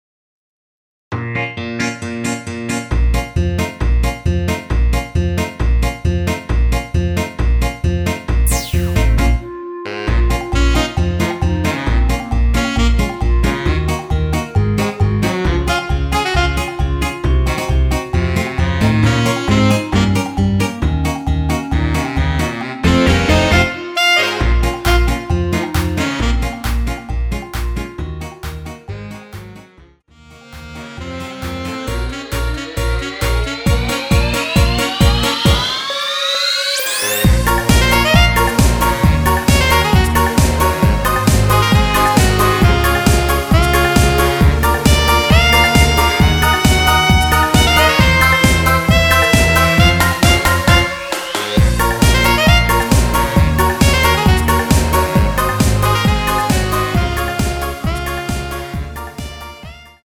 (-8)내린 멜로디 포함된 MR이며 멜로디 음색을 앞부분과 뒷부분을 다르게 제작하였습니다.(미리듣기 참조)
Bbm
앞부분30초, 뒷부분30초씩 편집해서 올려 드리고 있습니다.
(멜로디 MR)은 가이드 멜로디가 포함된 MR 입니다.